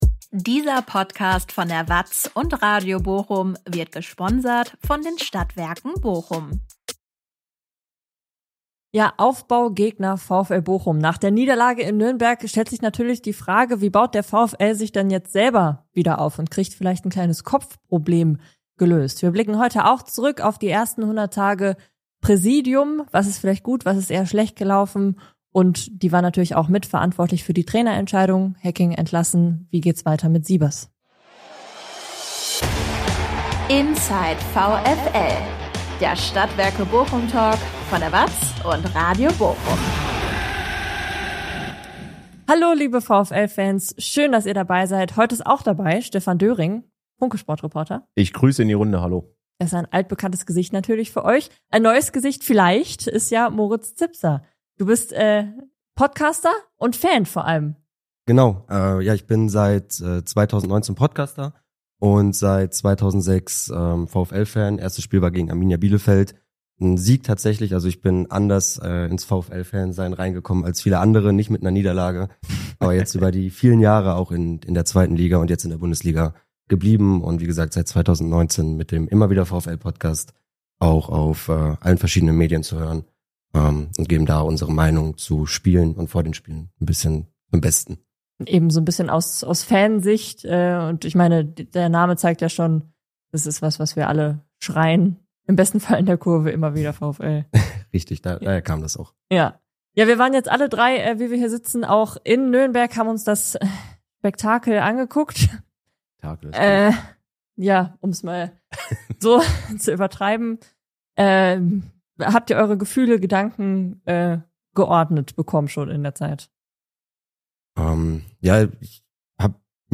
Darüber sprechen wir in einer neuen Talk-Folge.